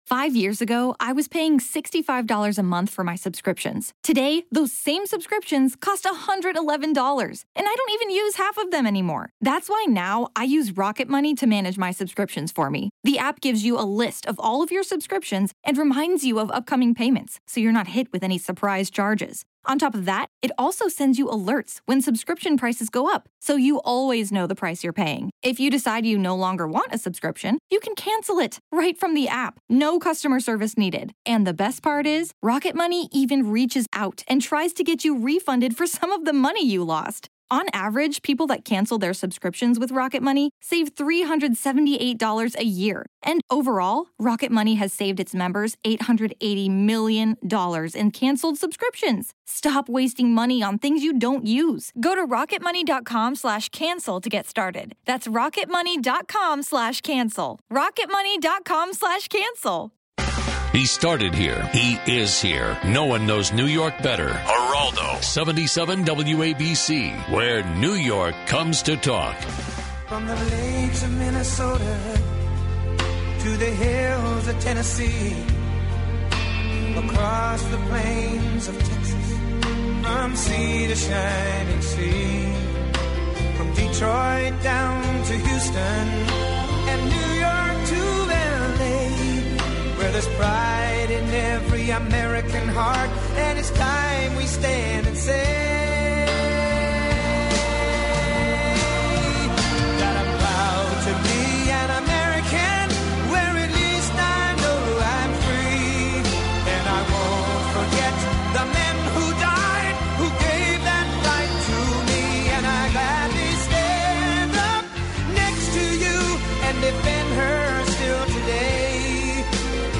Geraldo covers the latest news & headlines, live and local!